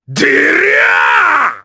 Ricardo-Vox_Attack4_jp.wav